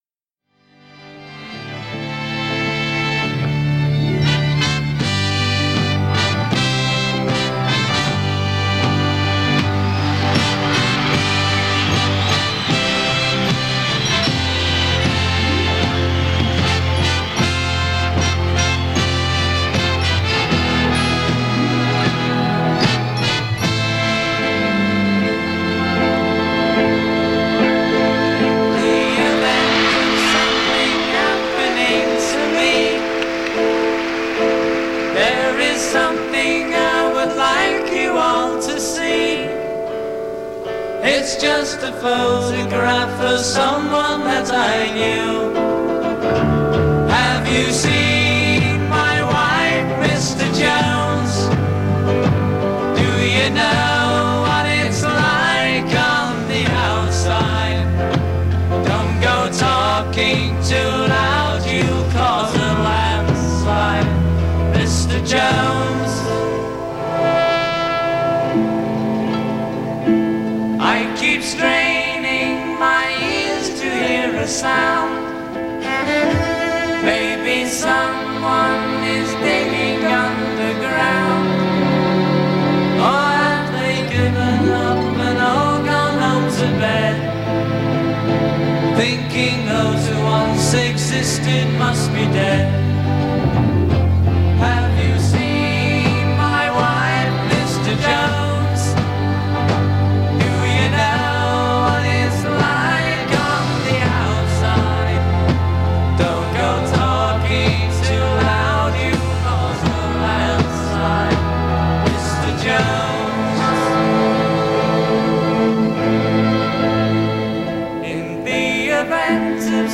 Recorded in Festival Hall, July 15, 1971
in concert from Festival Hall, Melbourne Australia